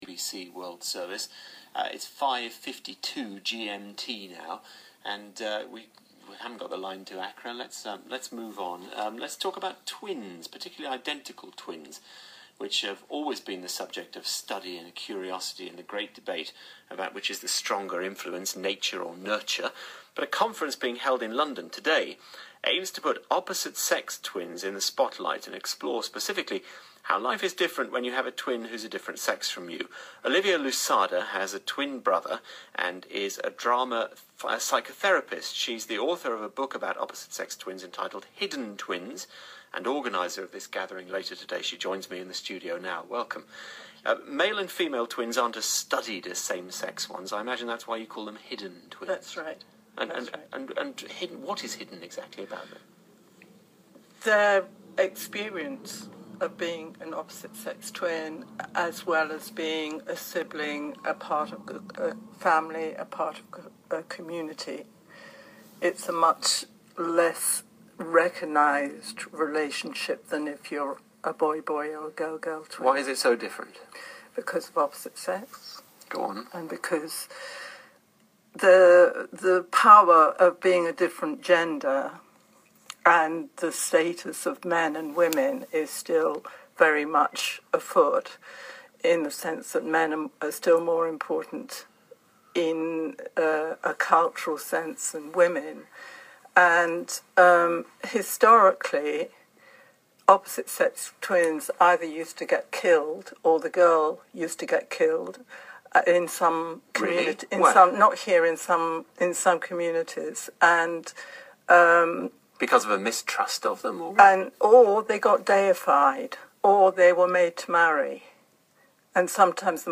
Interview on BBC World Service